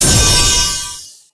multishot.wav